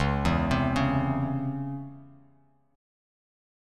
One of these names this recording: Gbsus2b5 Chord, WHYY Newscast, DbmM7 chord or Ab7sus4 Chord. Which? DbmM7 chord